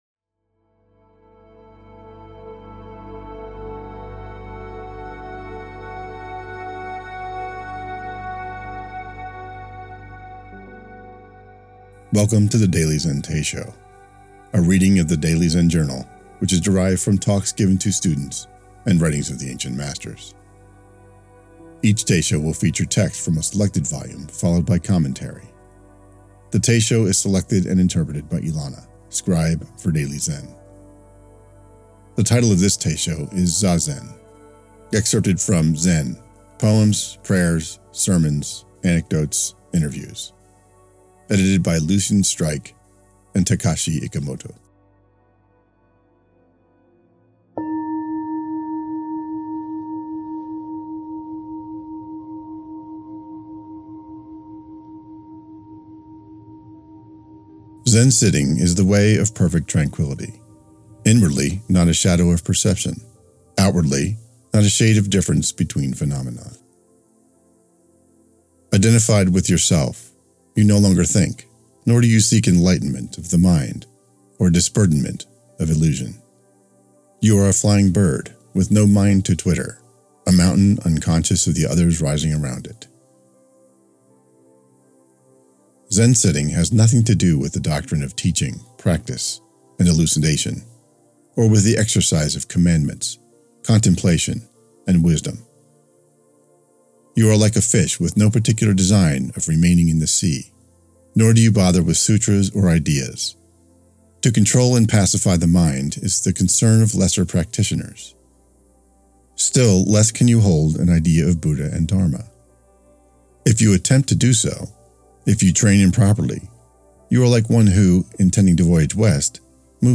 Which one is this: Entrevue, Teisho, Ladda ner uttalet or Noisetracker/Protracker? Teisho